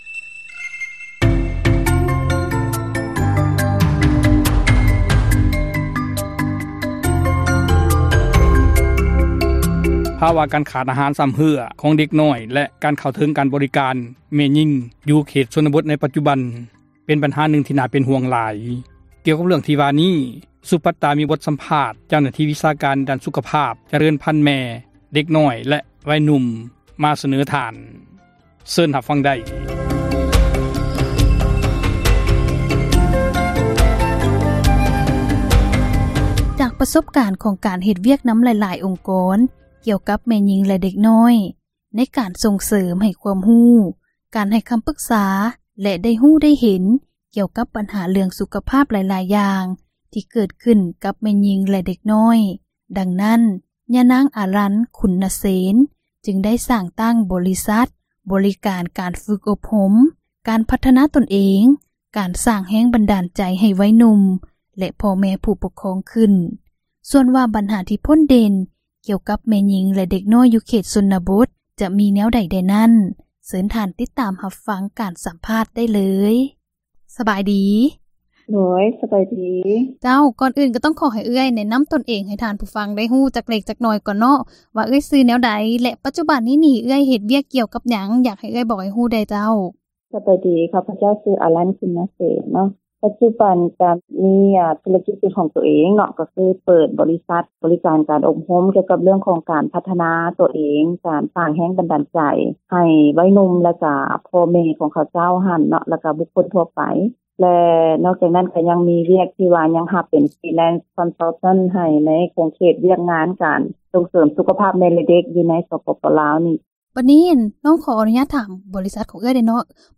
ທີ່ທ່ານໄດ້ຮັບຟັງສິ້ນສຸດລົງ ໄປນັ້ນ ແມ່ນການສໍາພາດ ເຈົ້າໜ້າທີ່ ວິຊາການດ້ານສຸຂພາບຈະເຣີນພັນ ແມ່ແລະເດັກນ້ອຍ ແລະວັຍໜຸ່ມ